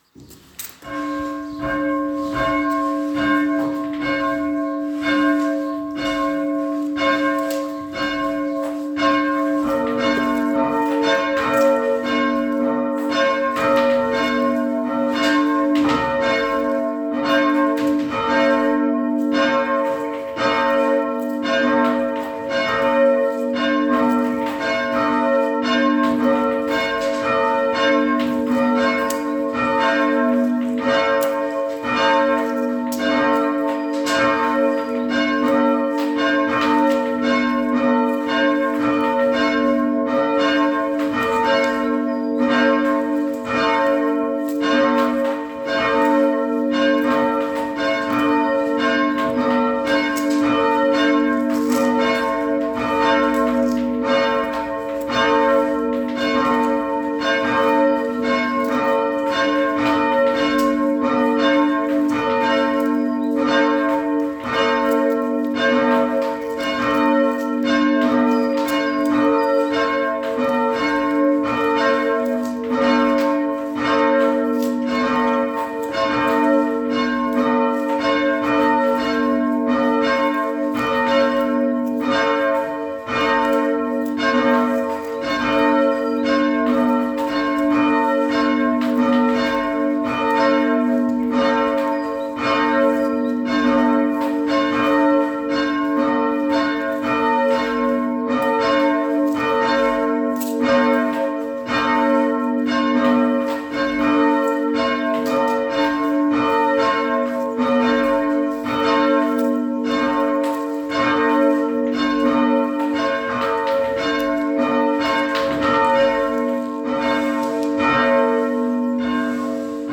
Ostersonntag Glocken Diedersdorf oder zum Herunterladen Ostermorgen oder zum Herunterladen Ostergedanken oder zum Herunterladen Christ ist erstanden oder zum Herunterladen Veröffentlicht am 11.
Glocken-Diedersdorf.mp3